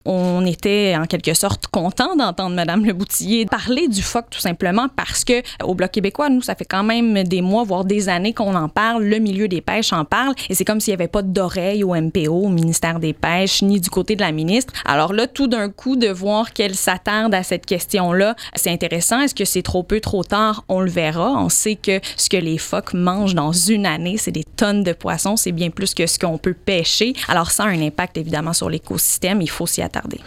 En entrevue sur nos ondes à l’émission Les preuves des faits, l’élue gaspésienne a réitéré son intention de briguer la nouvelle circonscription de Gaspésie–Îles-de-la-Madeleine–Listuguj lors du prochain scrutin fédéral.